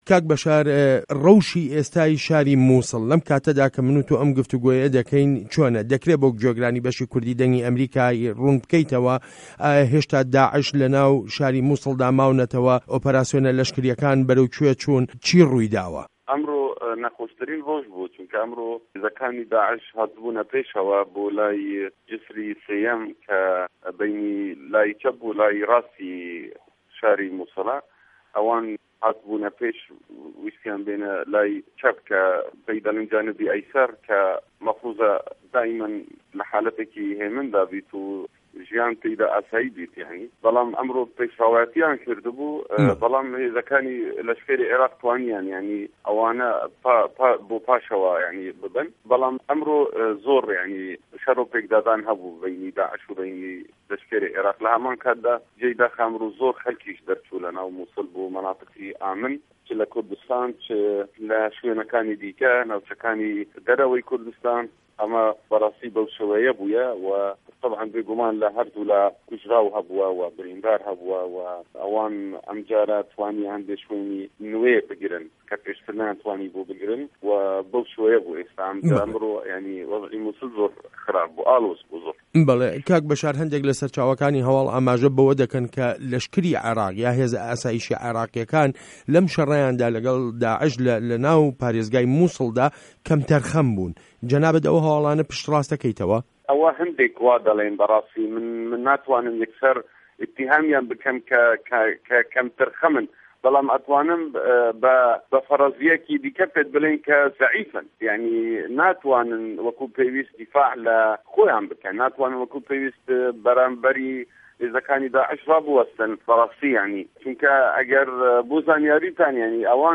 وتووێژ له‌گه‌ڵ به‌شار کیکی